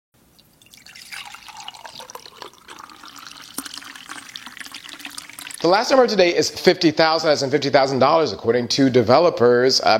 audio_dataset / audio /running_water /-Ft_FM2Bm5U.mp3